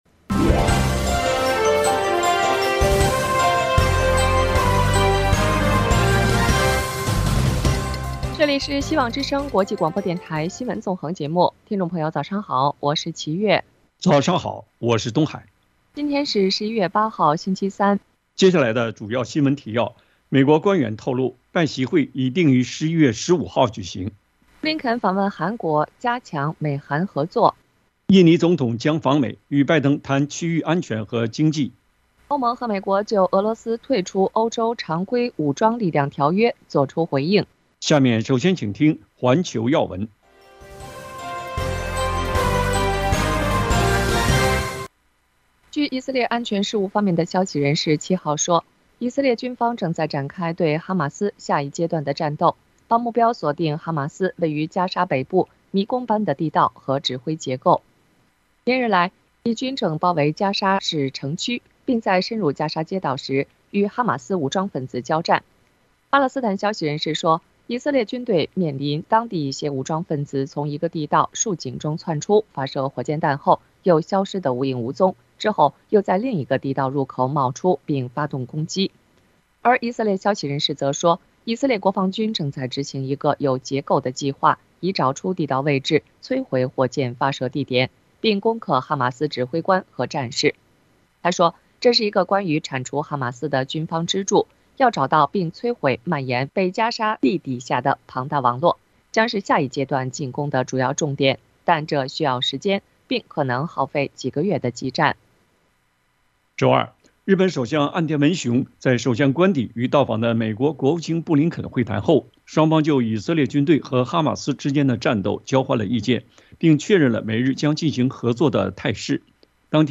以色列攻进加沙市中心 白宫反对以色列战后重新占领【晨间新闻】